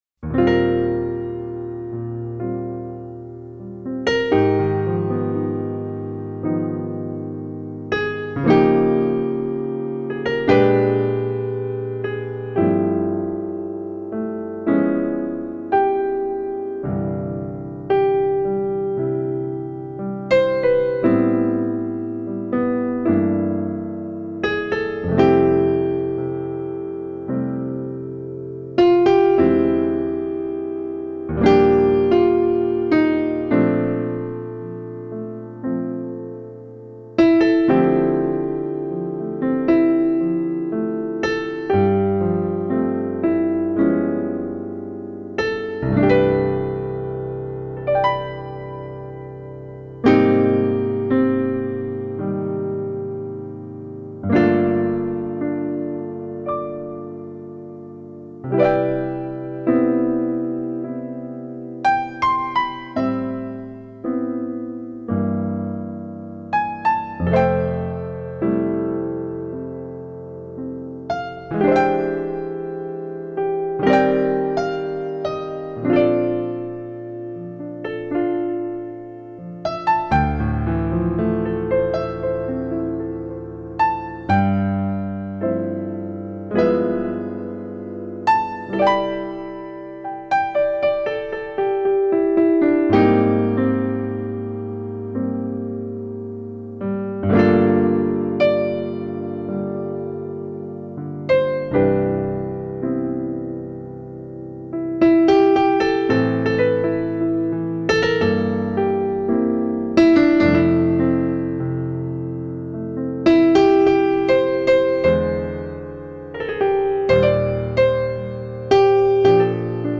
Contemporary world jazz with a caribean touch